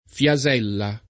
[ f L a @$ lla ]